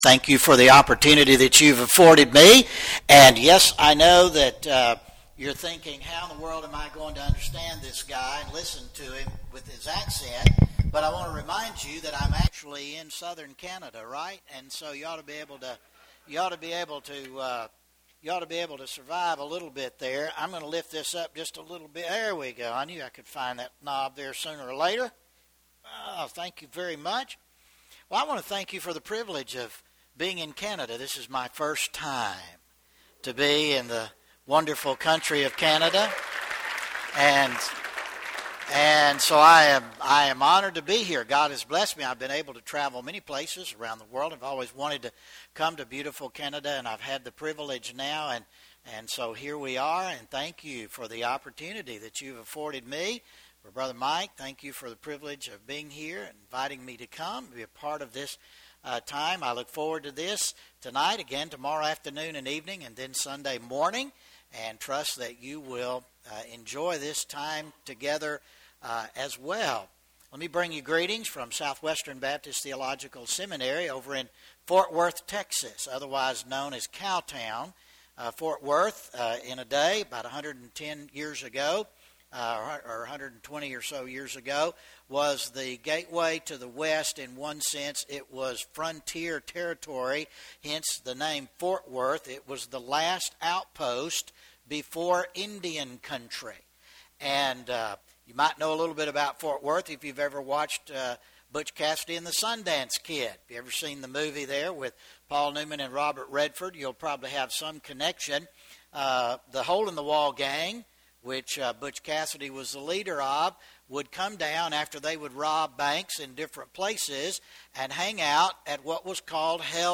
Series: 2017 Bible Conference